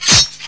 ClavaEspada.WAV